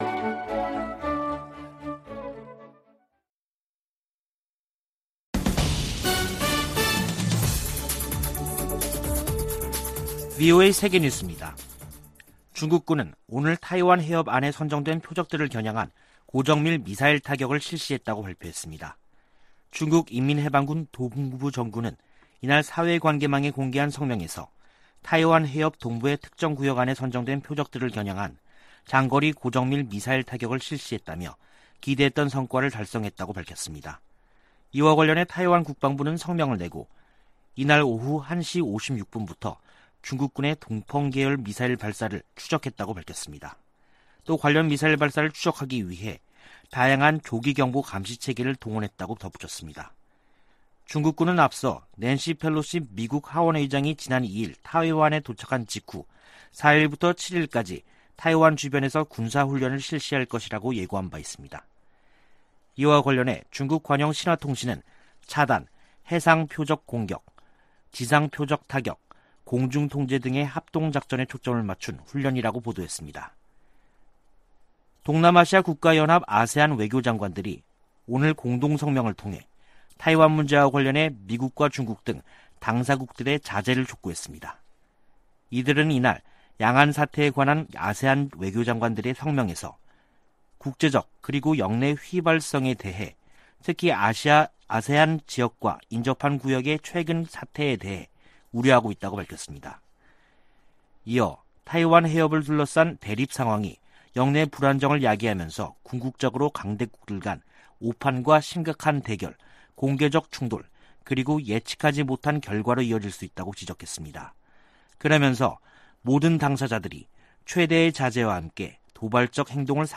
VOA 한국어 간판 뉴스 프로그램 '뉴스 투데이', 2022년 8월 4일 3부 방송입니다. 윤석열 한국 대통령이 낸시 펠로시 미 하원의장의 방한 행보에 관해 미한 대북 억지력의 징표라고 말했습니다. 미국은 한국에 대한 확장억제 공약에 매우 진지하며, 북한이 대화를 거부하고 있지만 비핵화 노력을 계속할 것이라고 국무부가 강조했습니다. 미국과 한국의 합참의장이 화상대화를 갖고 동맹과 군사협력 등에 관해 논의했습니다.